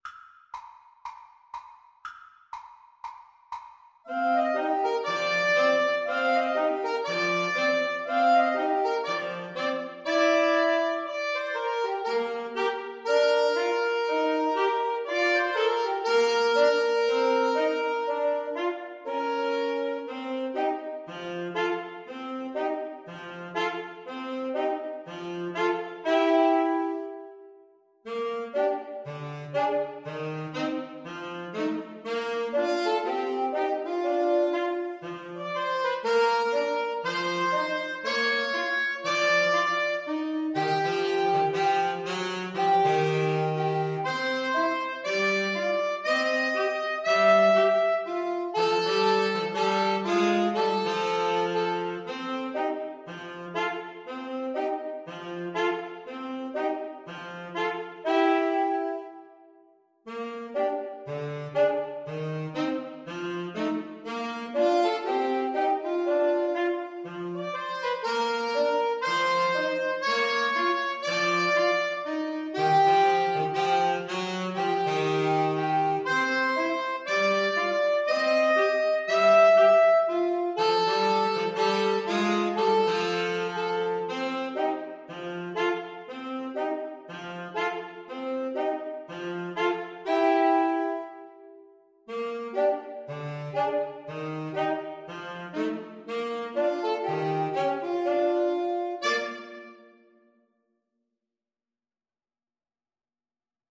Allegro (View more music marked Allegro)
Woodwind Trio  (View more Intermediate Woodwind Trio Music)
Jazz (View more Jazz Woodwind Trio Music)